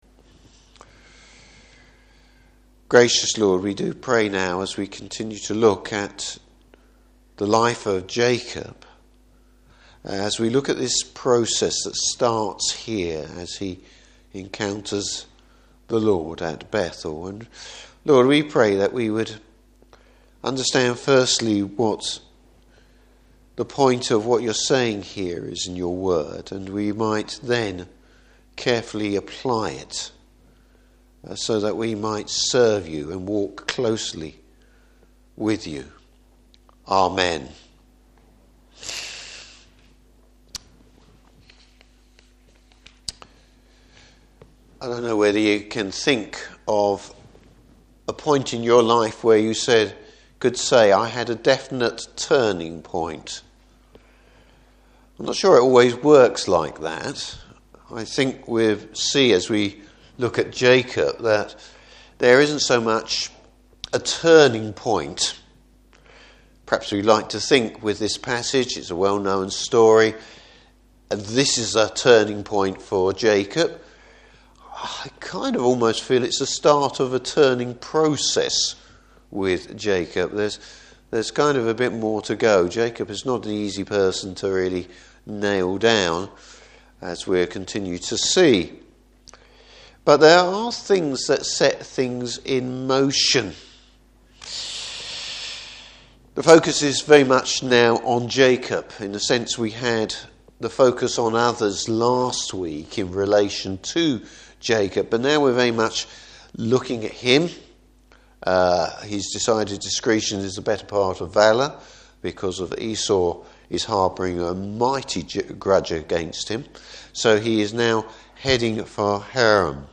Service Type: Evening Service Jacob’s surprising meeting with the Lord.